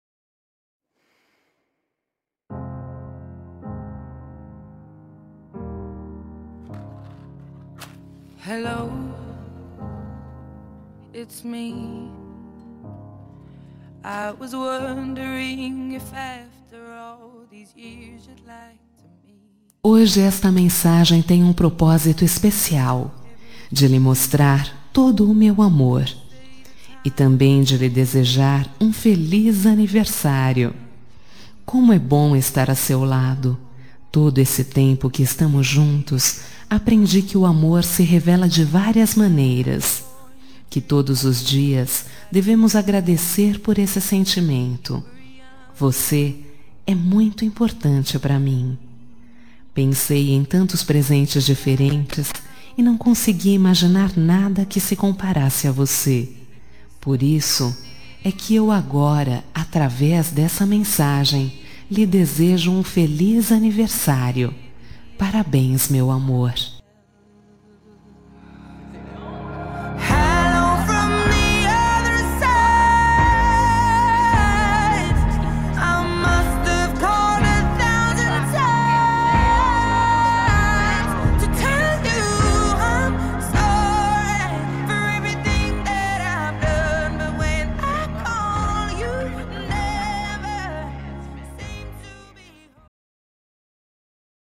Telemensagem de Aniversário Romântico – Voz Feminina – Cód: 1013